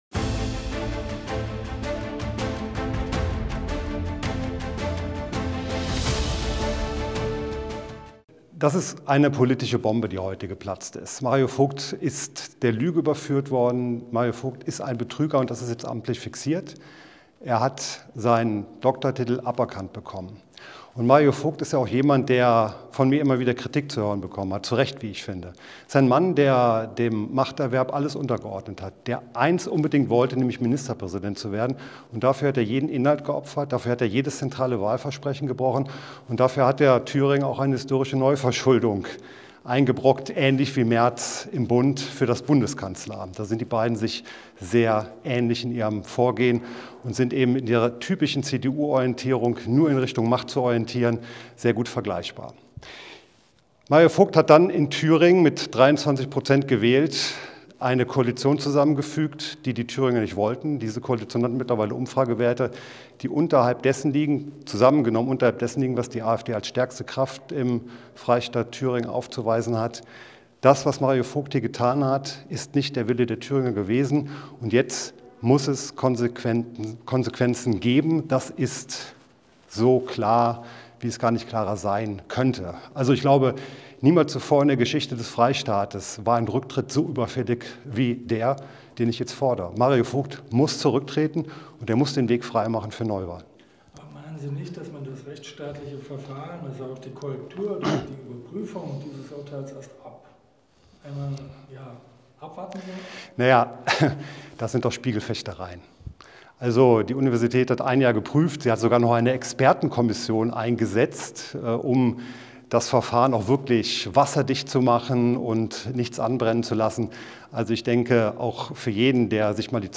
Über mehrere Wochen wurde über das Verhalten des Thüringer Innenministeriums bei internen Ermittlungen der Polizei gegen Polizisten in Saalfeld sowie Mitglieder der Gewerkschaft der Polizei (GdP) landespolitisch heftig diskutiert. Die Debatte gipfelte in einem von der AfD-Fraktion beantragten Sonderplenum des Thüringer Landtages am 16. Januar 2026. Wir dokumentieren an dieser Stelle die Rede von Sven Küntzel (Bündnis Sahra Wagenknecht) zu diesem Thema.